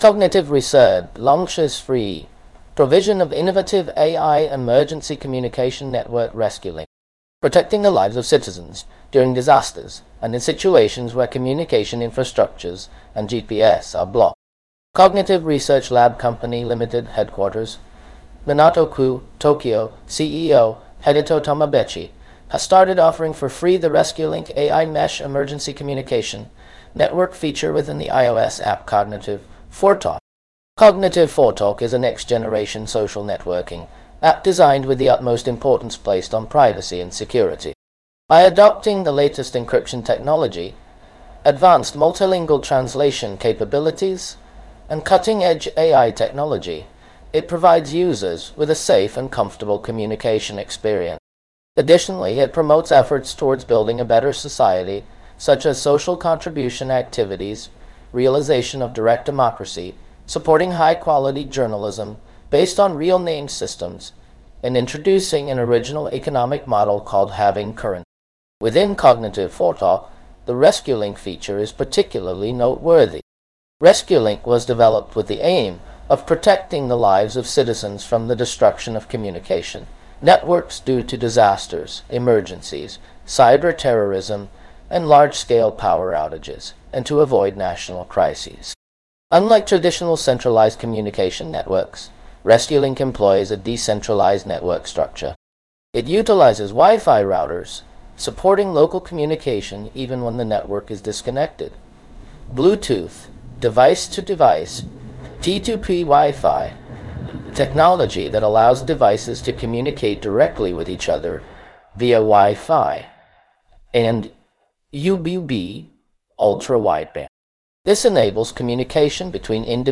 英語版音声プレスリリース